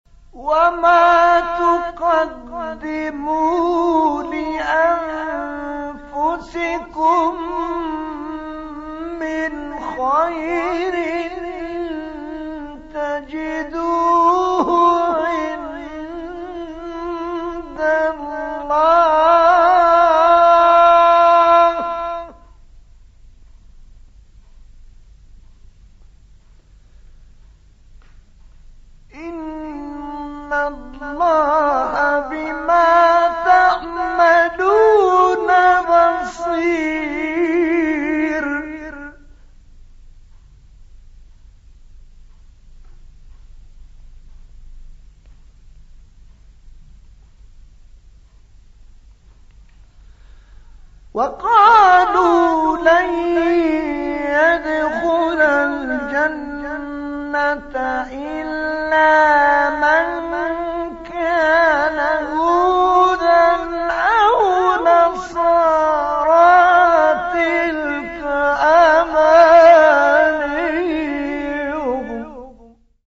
مقام صبا استاد طه‌ الفشنی | نغمات قرآن | دانلود تلاوت قرآن